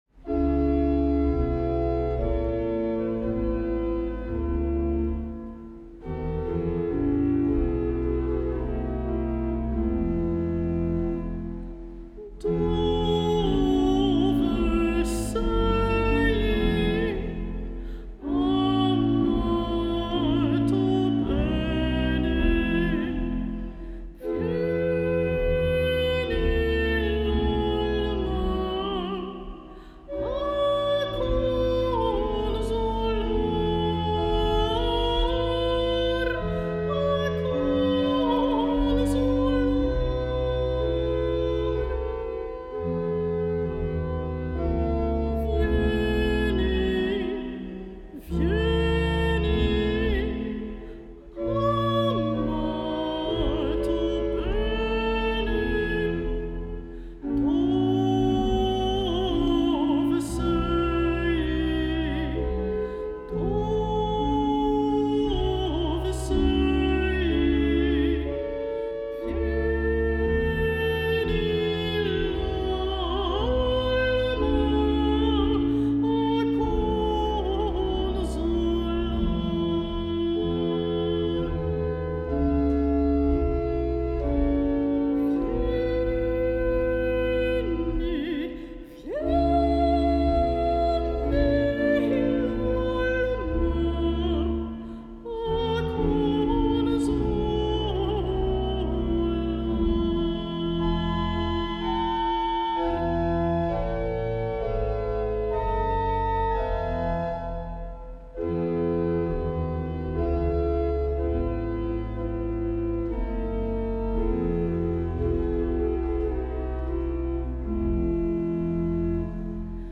Locatie: Paradijskerk, Rotterdam